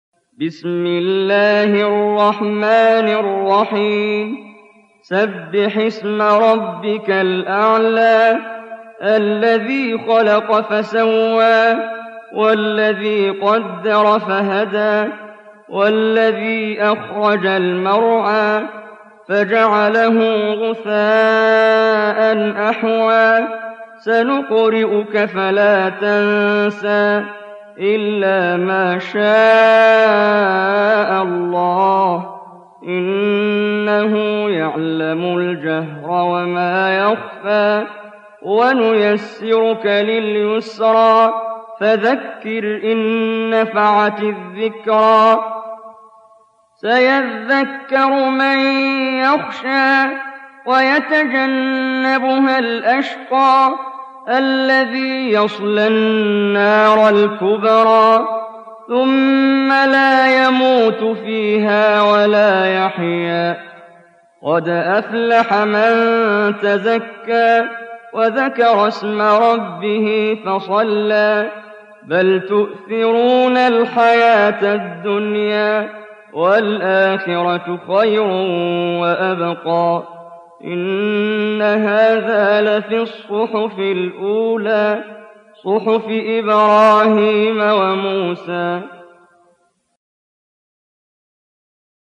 Surah Al-A'l� سورة الأعلى Audio Quran Tarteel Recitation
Surah Sequence تتابع السورة Download Surah حمّل السورة Reciting Murattalah Audio for 87. Surah Al-A'l� سورة الأعلى N.B *Surah Includes Al-Basmalah Reciters Sequents تتابع التلاوات Reciters Repeats تكرار التلاوات